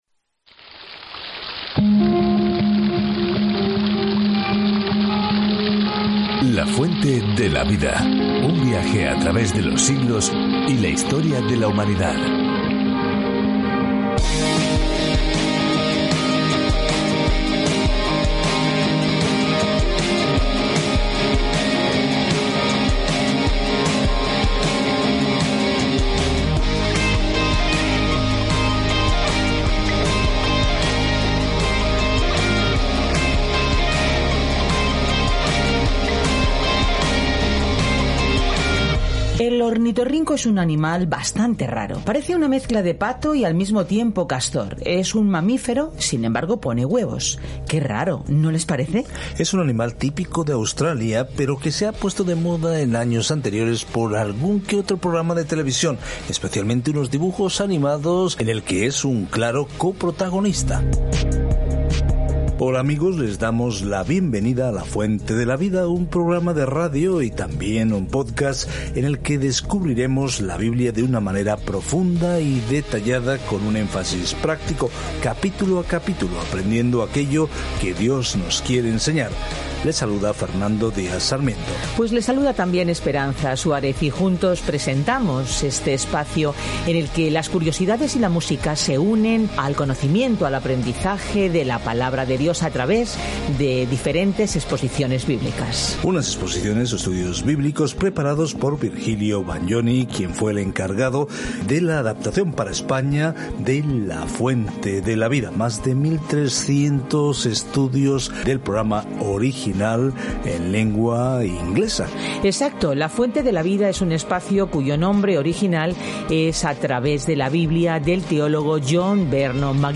Escritura 1 SAMUEL 18:1-30 1 SAMUEL 19 1 SAMUEL 20:1 Día 10 Iniciar plan Día 12 Acerca de este Plan Primero Samuel comienza con Dios como rey de Israel y continúa la historia de cómo Saúl, y luego David, se convirtió en rey. Viaje diariamente a través de Primera de Samuel mientras escucha el estudio de audio y lee versículos seleccionados de la palabra de Dios.